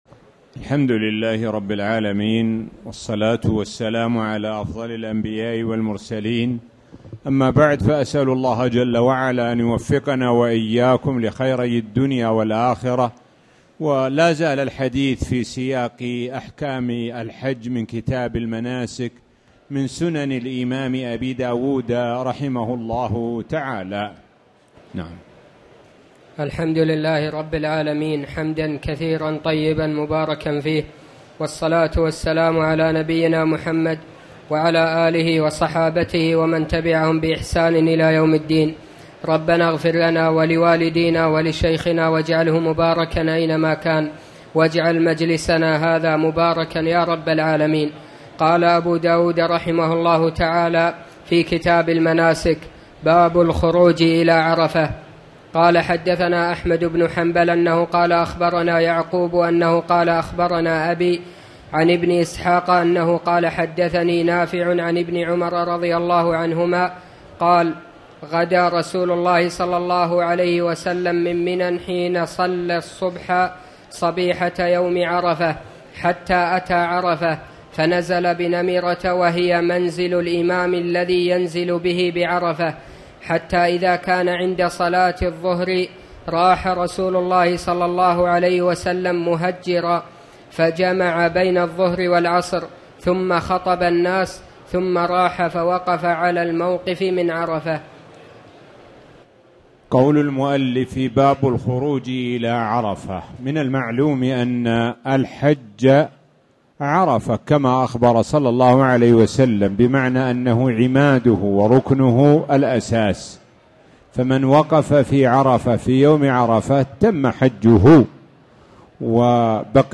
تاريخ النشر ٣ ذو الحجة ١٤٣٨ هـ المكان: المسجد الحرام الشيخ: معالي الشيخ د. سعد بن ناصر الشثري معالي الشيخ د. سعد بن ناصر الشثري أحكام الحج